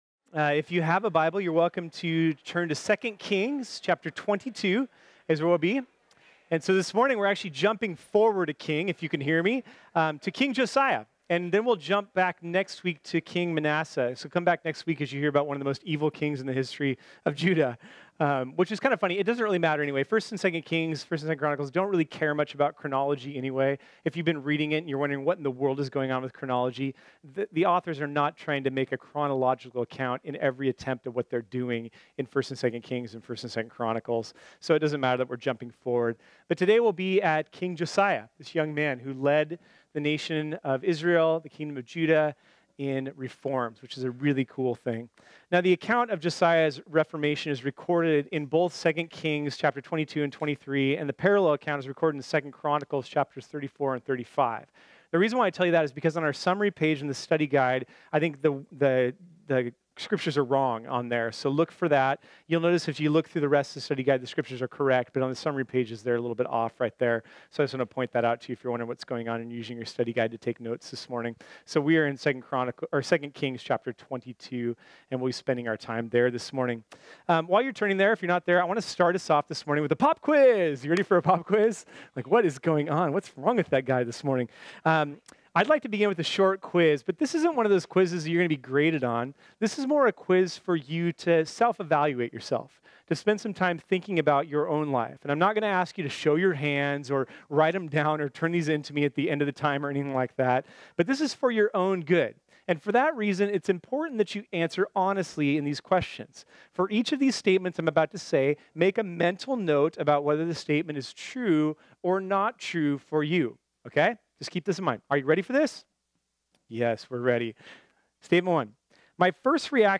This sermon was originally preached on Sunday, August 5, 2018.